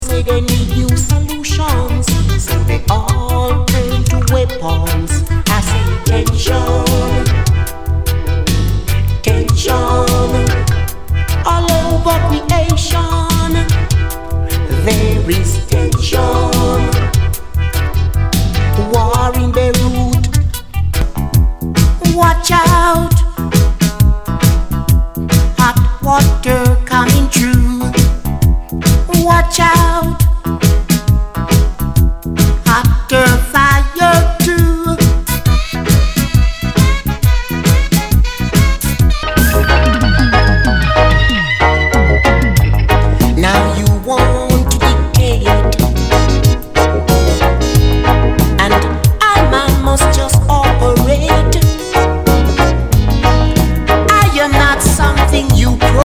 類別 雷鬼
ナイス！ルーツ・レゲエ！！